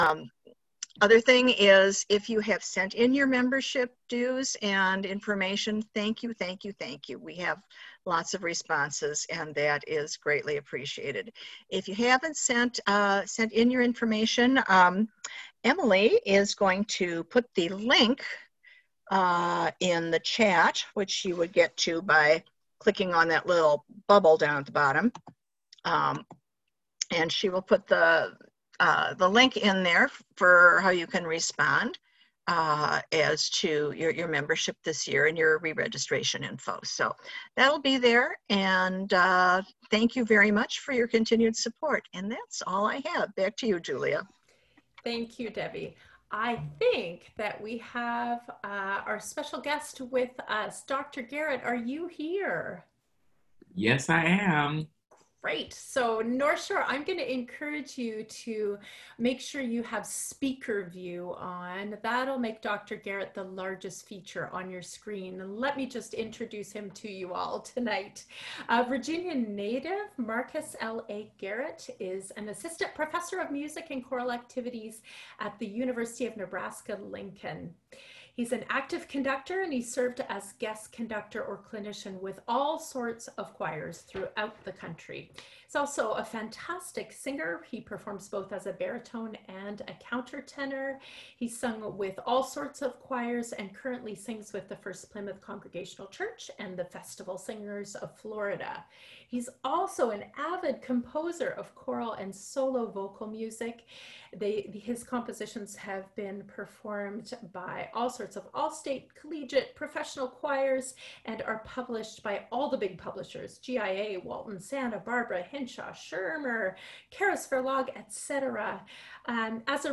Audio recording from November 29, 2020 NSCS rehearsal